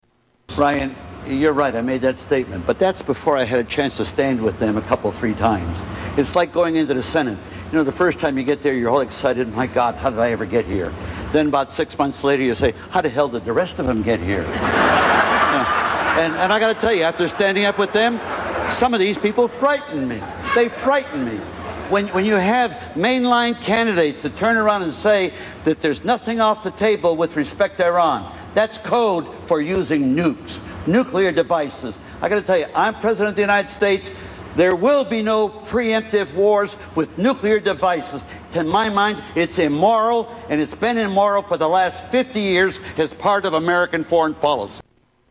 Tags: Political Mike Gravel Presidential Candidate Democratic Mike Gravel Speeches